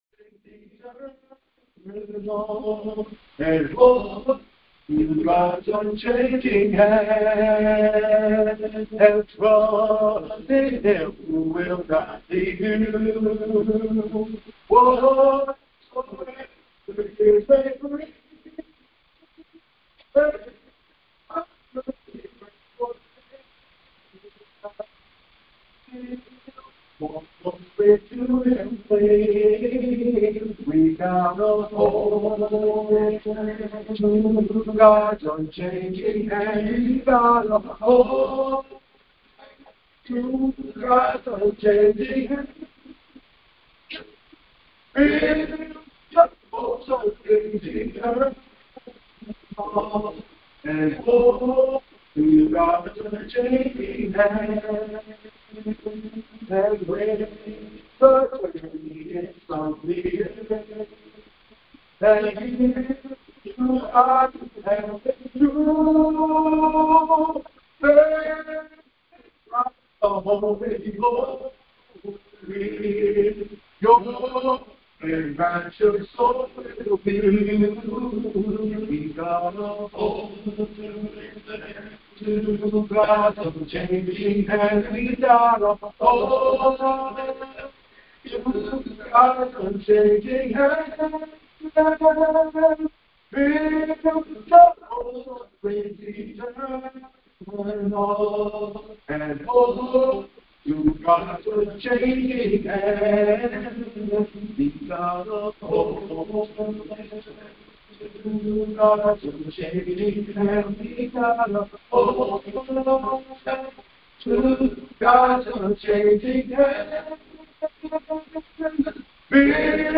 Sunday Morning Service 1.4.26